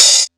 drewidehat.wav